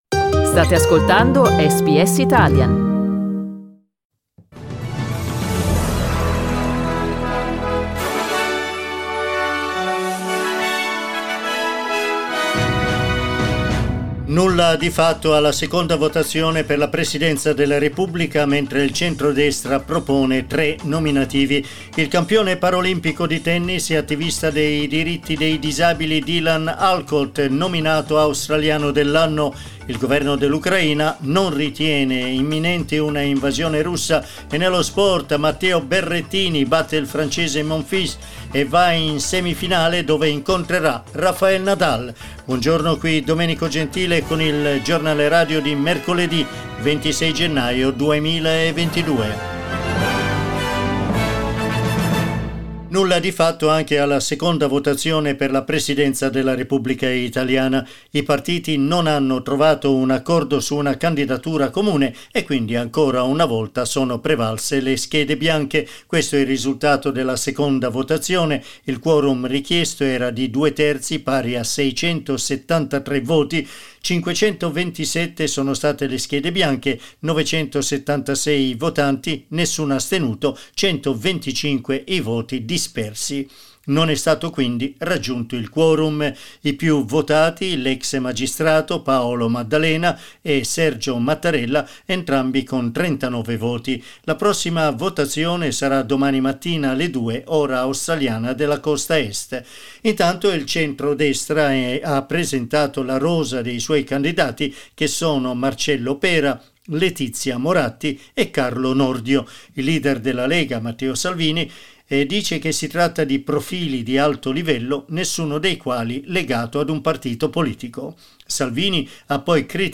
Giornale radio mercoledì 26 gennaio 2022
26jan_gr_pod_con_sigle.mp3